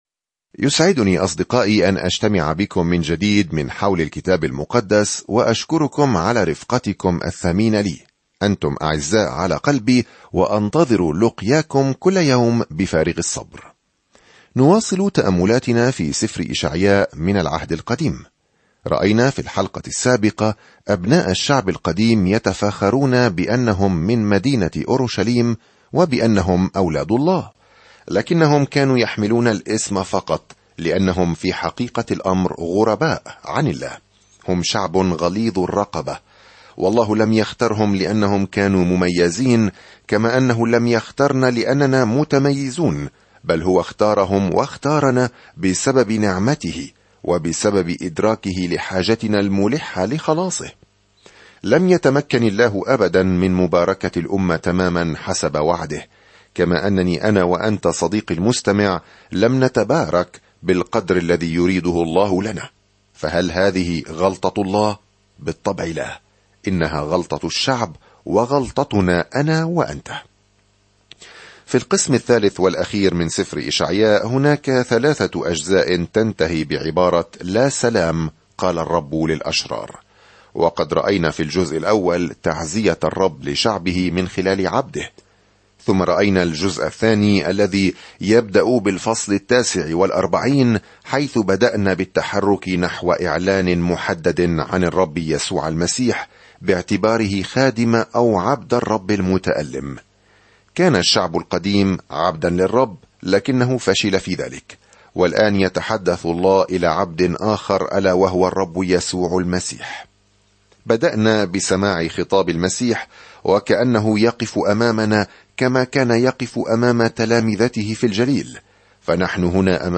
الكلمة إِشَعْيَاءَ 2:50-11 إِشَعْيَاءَ 51 يوم 36 ابدأ هذه الخطة يوم 38 عن هذه الخطة ويصف إشعياء، المسمى "الإنجيل الخامس"، ملكًا وخادمًا قادمًا "سيحمل خطايا كثيرين" في وقت مظلم عندما يسيطر الأعداء السياسيون على يهوذا. سافر يوميًا عبر إشعياء وأنت تستمع إلى الدراسة الصوتية وتقرأ آيات مختارة من كلمة الله.